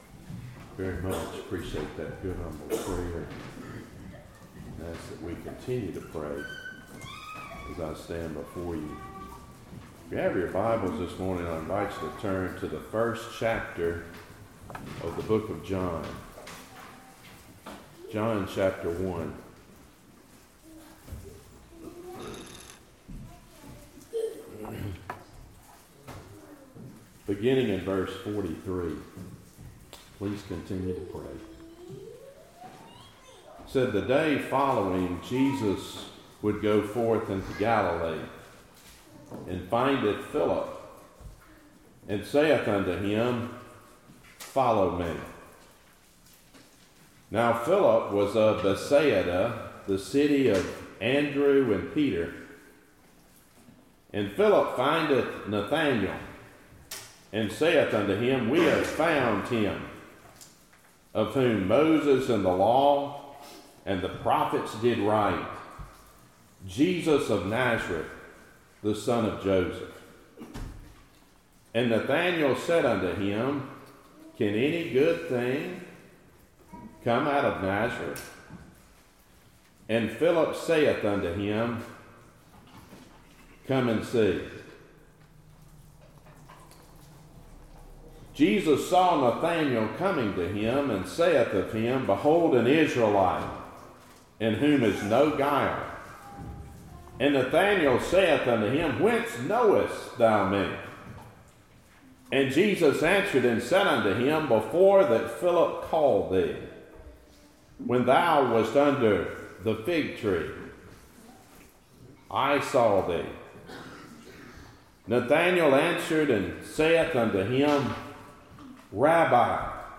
Topic: Sermons